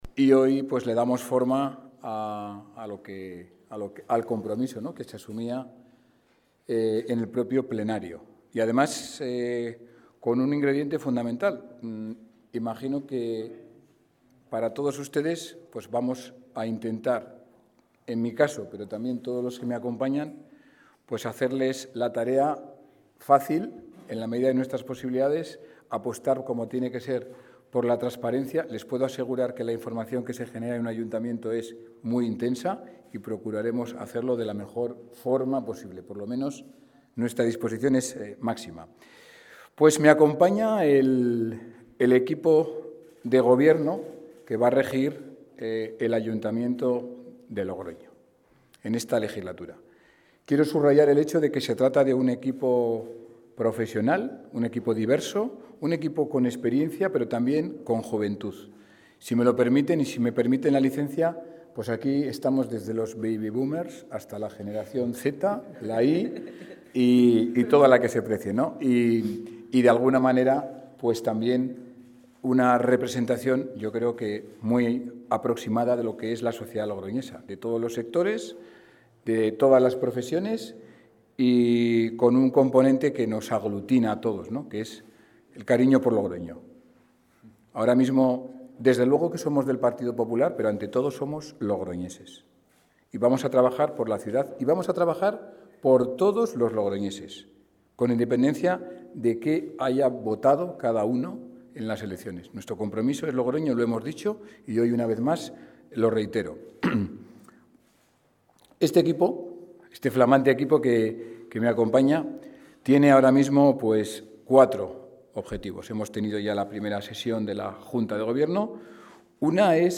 El alcalde de Logroño, Conrado Escobar, ha detallado esta mañana el diseño de su Gobierno local para los próximos cuatro años tras tomar posesión de su cargo el pasado sábado.